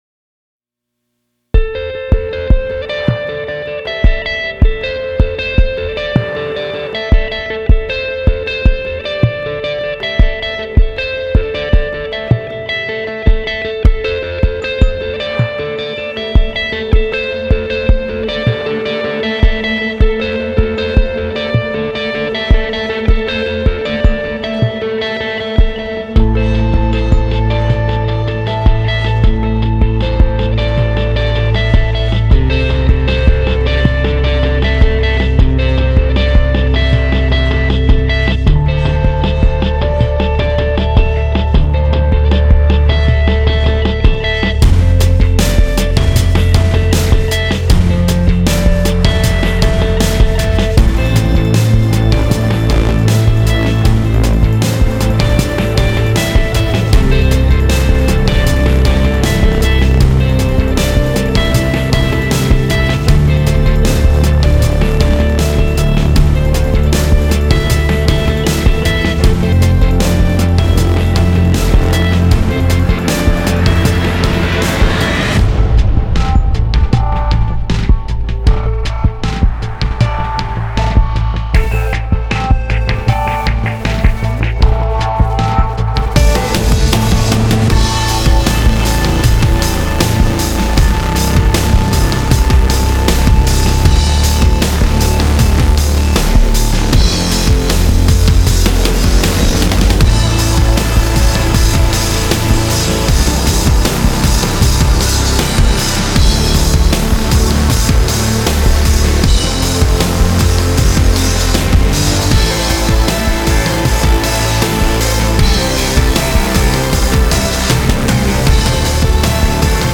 موسیقی بیکلام
موسیقی حماسی